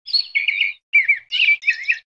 Birds.ogg